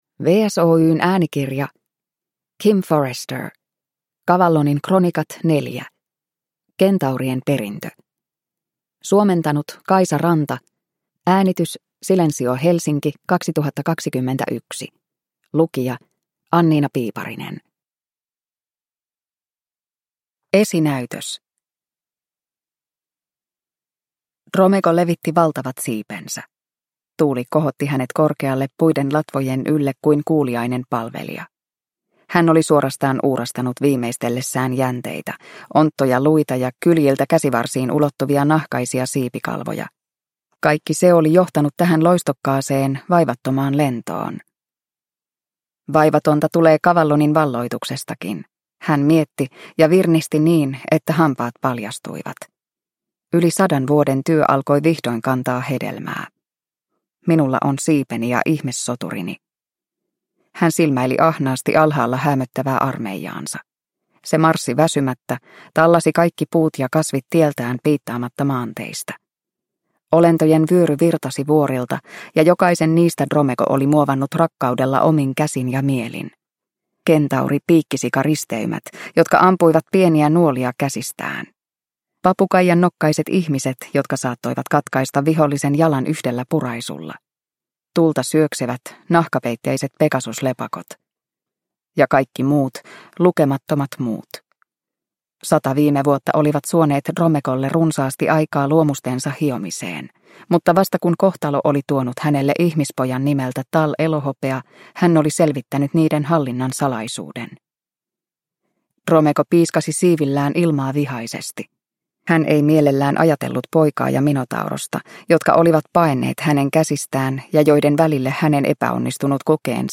Cavallonin kronikat 4: Kentaurien perintö – Ljudbok – Laddas ner